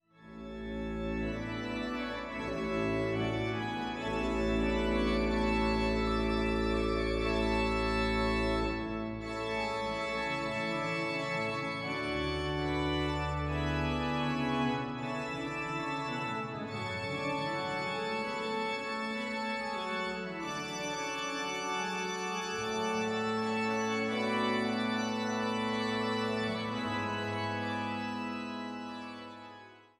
Orgel in Freiberg